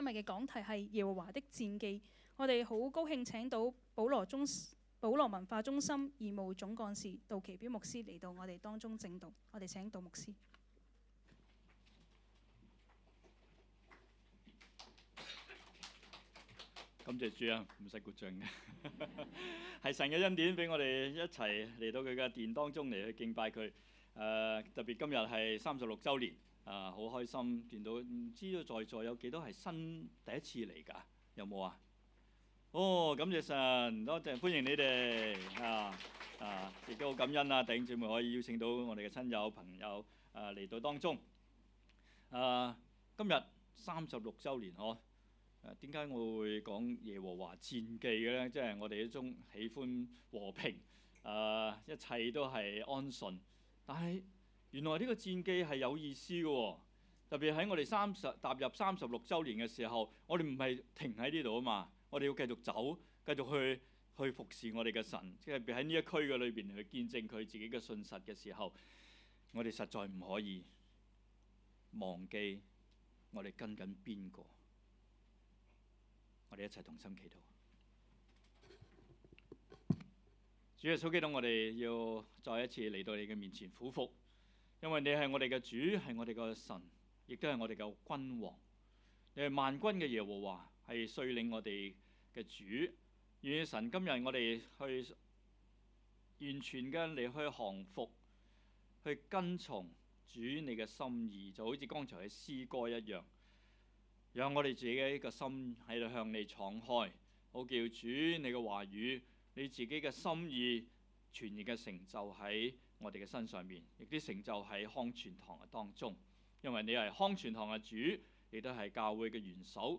講道 ：耶和華的戰記